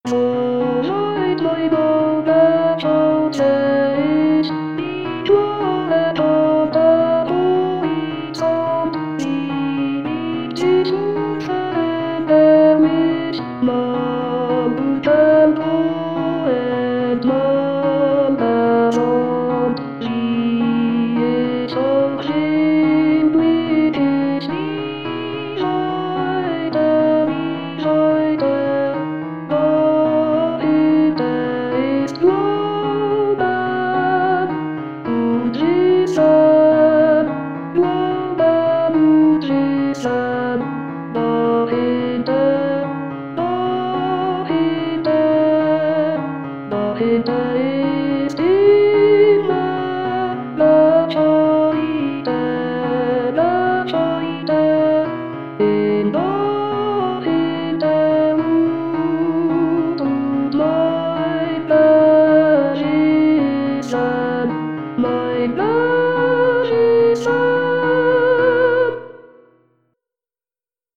mp3-Aufnahme: Wiedergabe mit Gesang